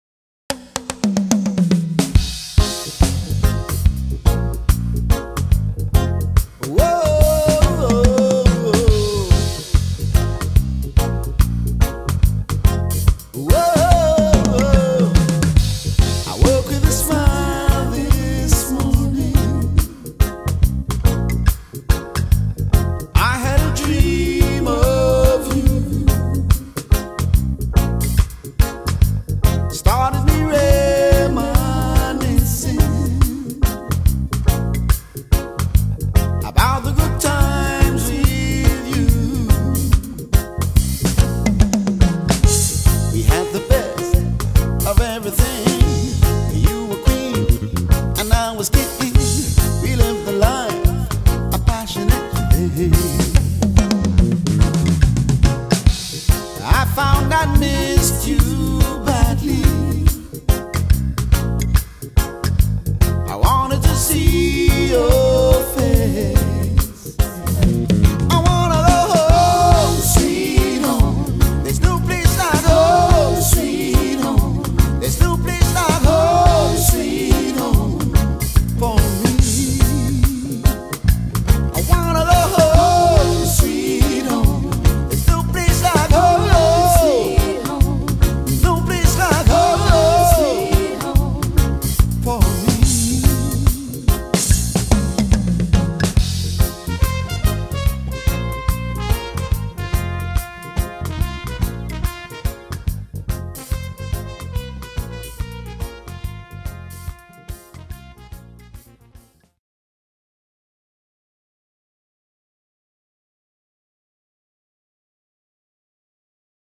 TThe Trio: Bass, Guitar & Drums, self-contained vocals.
Soca, Calypso, Reggae, Compa, Zouk and African music.